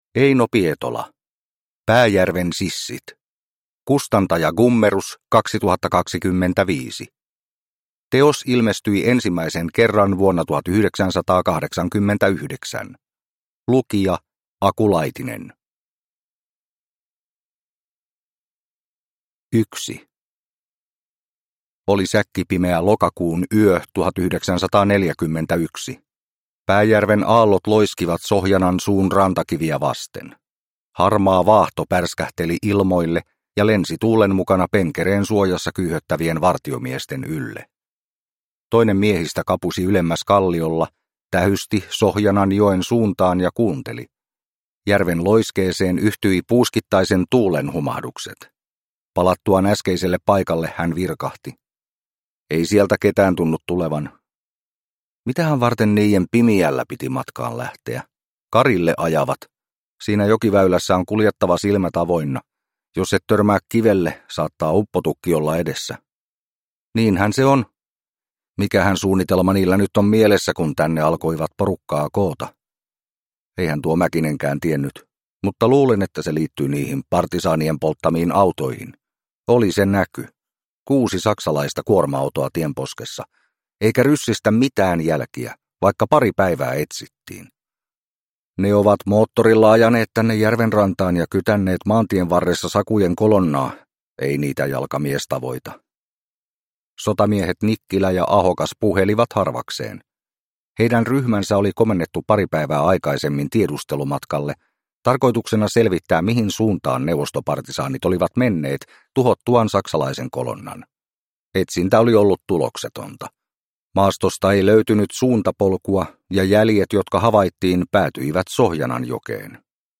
Pääjärven sissit (ljudbok) av Eino Pietola | Bokon